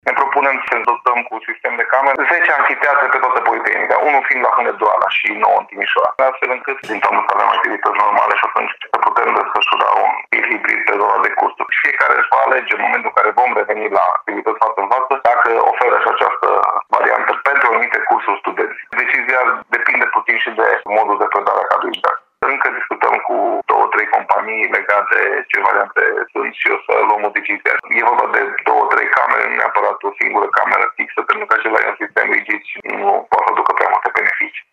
Echipamentele vor permite organizarea cursurilor în mod hibrid din toamna acestui an, în funcție de preferințele profesorilor și ale studenților, spune rectorul Universității Politehnica, Florin Drăgan.